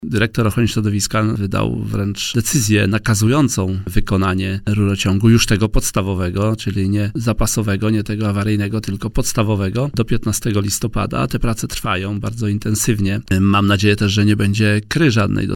– Potrzeba jeszcze czasu, aby całkowicie zniwelować straty środowiskowe – podkreślił na antenie Radia Warszawa w Poranku Siódma9 Minister Środowiska Henryk Kowalczyk.